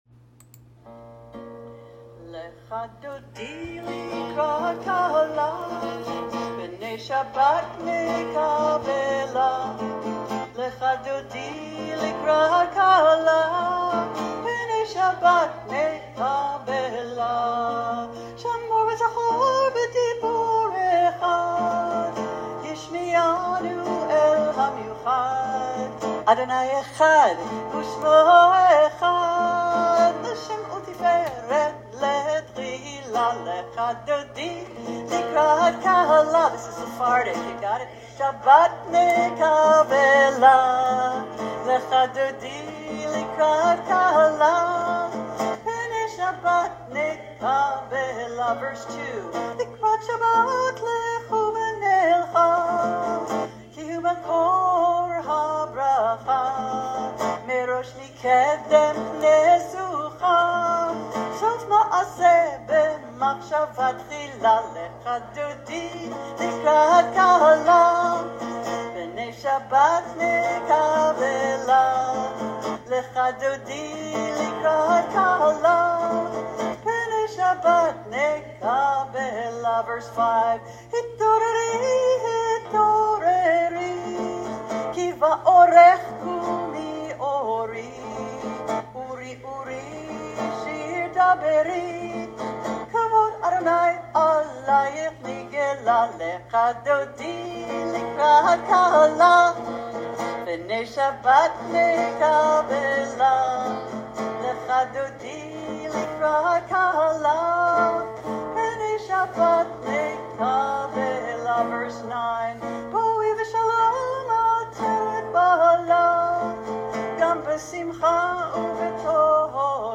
Page-138-Lecha-Dodi-2-Sephardic.mp3